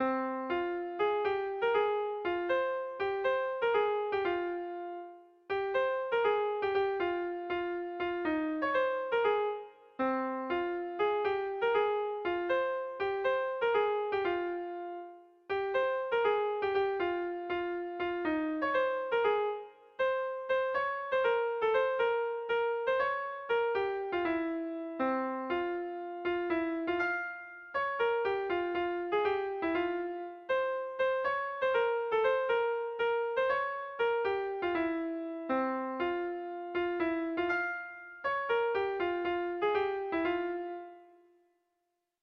Air de bertsos - Voir fiche   Pour savoir plus sur cette section
ABDE